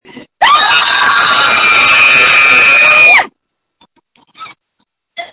Screams from November 29, 2020
• When you call, we record you making sounds. Hopefully screaming.